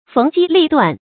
逢機立斷 注音： ㄈㄥˊ ㄐㄧ ㄌㄧˋ ㄉㄨㄢˋ 讀音讀法： 意思解釋： 猶言當機立斷。